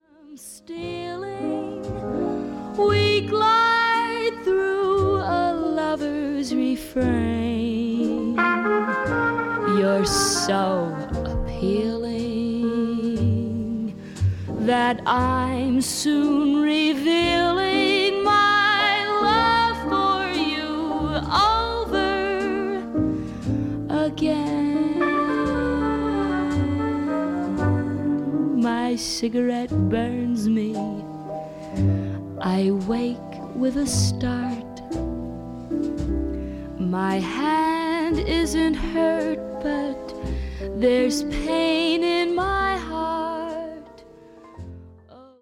この時代の歌手ならではの明朗快活っぷりに、深く息をするようなデリケートな歌い方もできるアメリカのシンガー
恋をテーマにしたスロー〜ミディアム・テンポの曲でほぼ占められる本作。